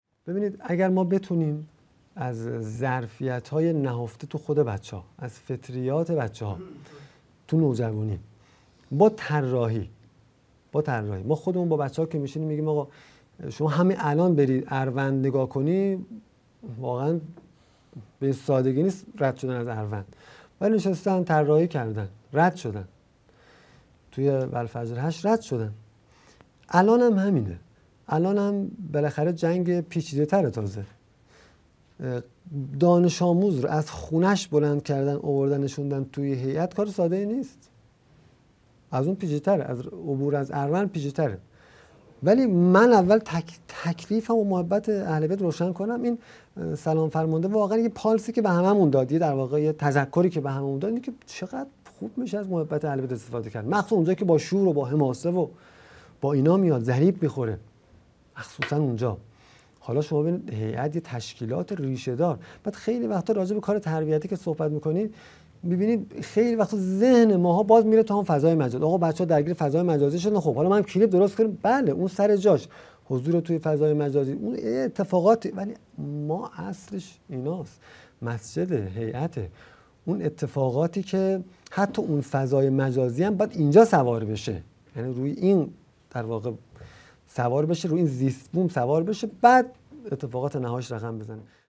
گزیده یازدهم از سومین سلسله نشست‌ های هیأت و نوجوانان
قم - اردبیهشت ماه 1402